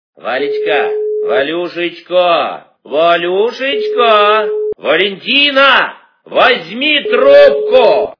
- Именные звонки